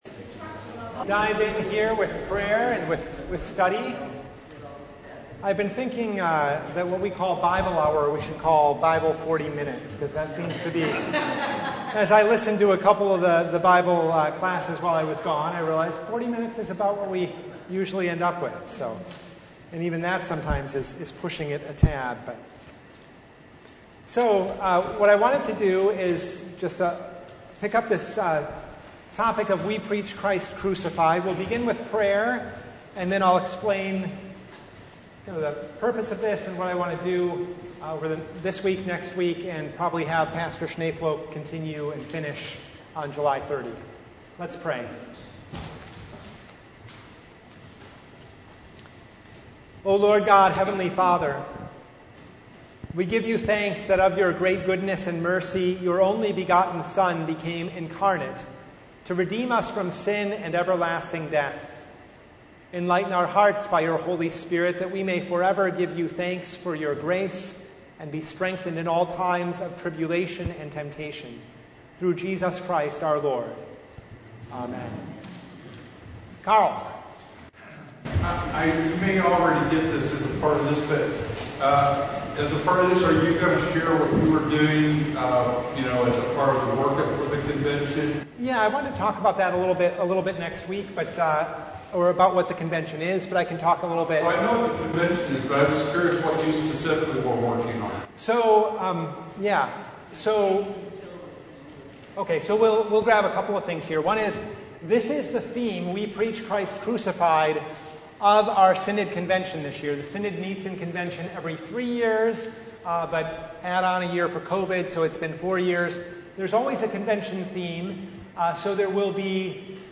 Service Type: Bible Hour